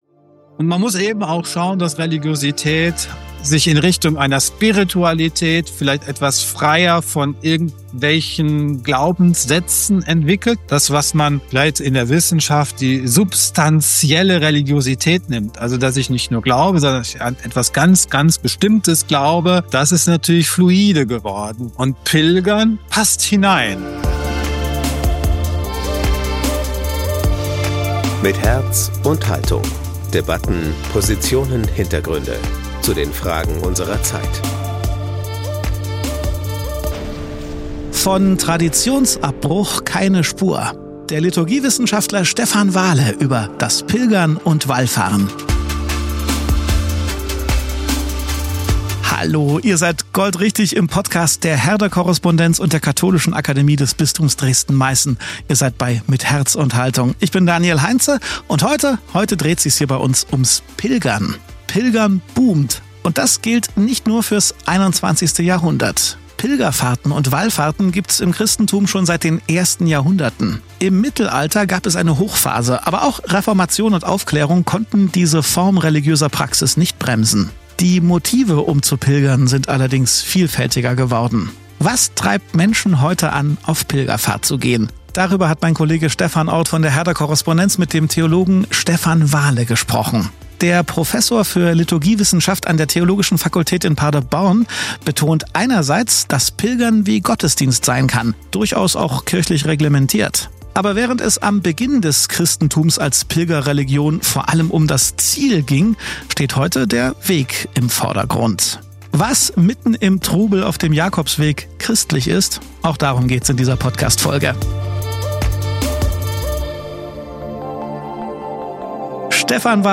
Was treibt Menschen heute an, auf Pilgerfahrt zu gehen? Darüber sprachen wir mit dem Theologen